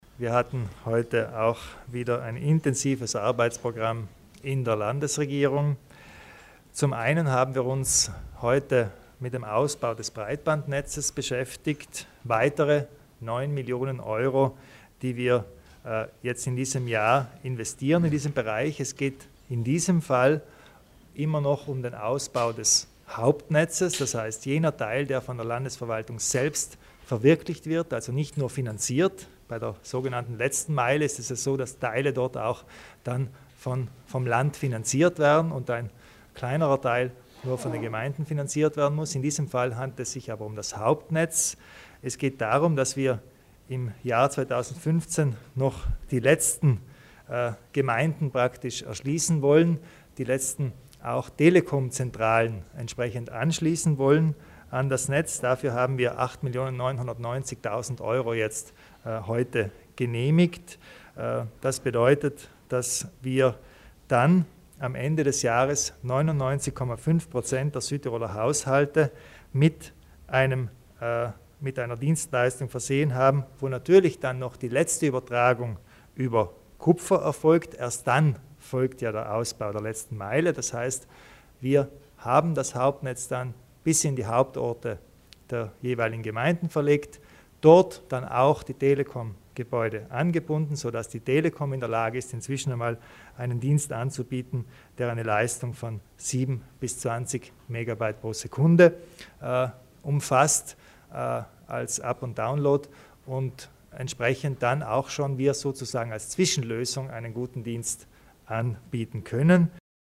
Landeshauptmann Kompatscher erläutert die neuen Investitionen ins Breitbandnetz